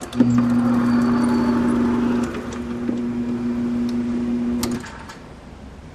Electric Door Close, Ambient